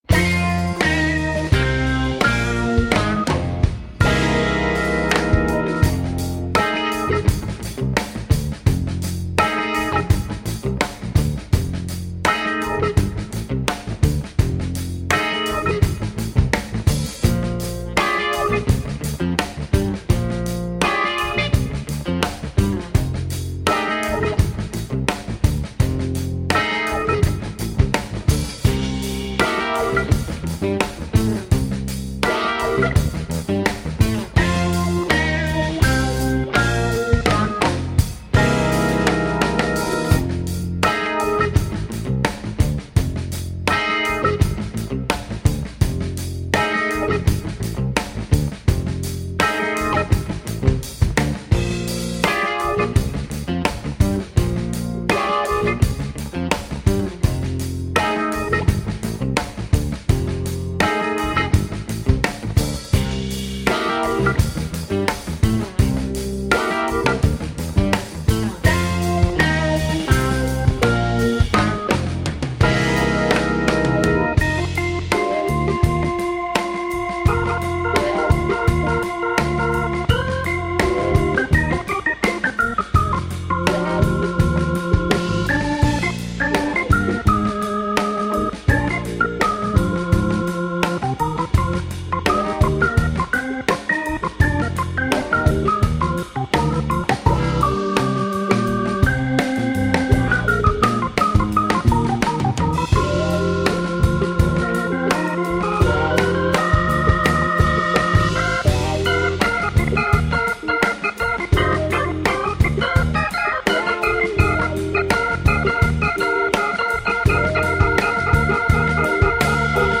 Boppin', swingin' joie de vivre whether it's jazz or blues.
plays bass, guitars and 6-string banjo
saxophonist
trumpet
trombone
drums
keyboards
funk
Recorded and mixed in his hometown of Stanstead
Tagged as: Jazz, Blues